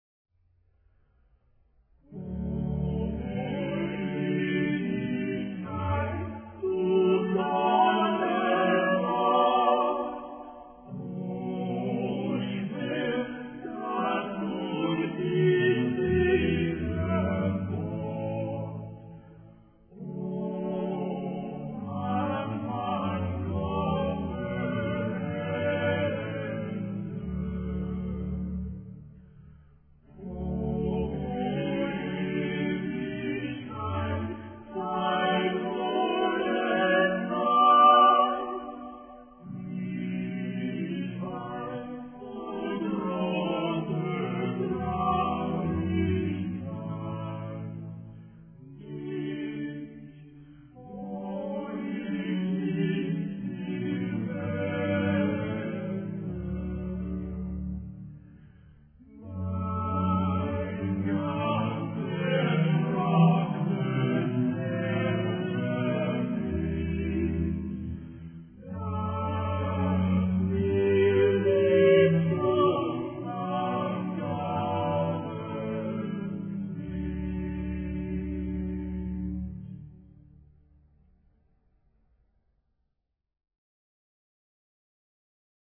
mp3 mono 8kbps